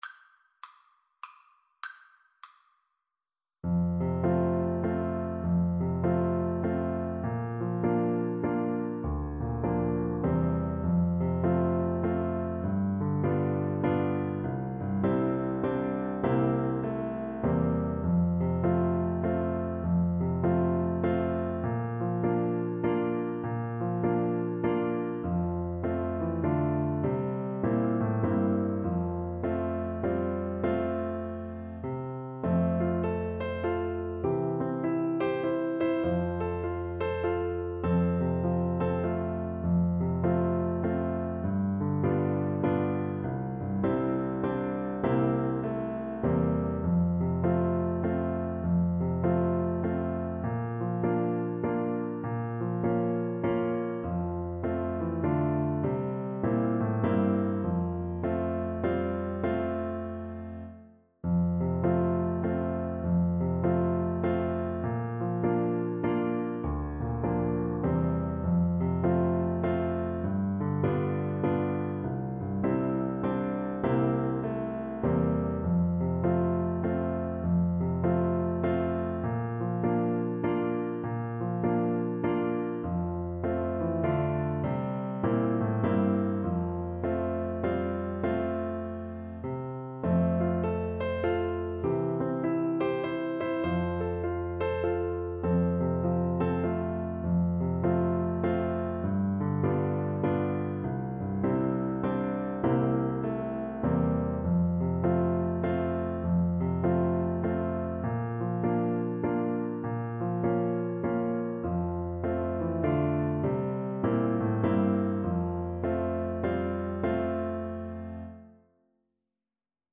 classic western song
With a swing = 100
3/4 (View more 3/4 Music)